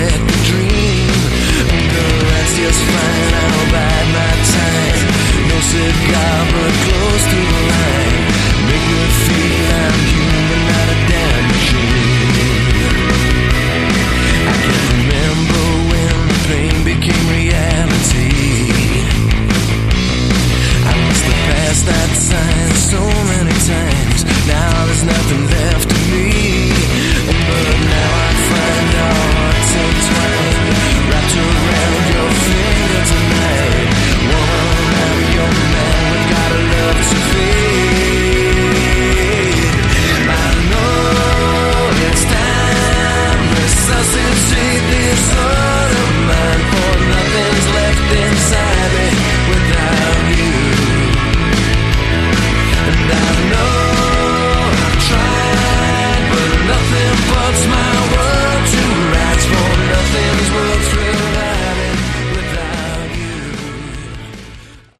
Category: AOR
drums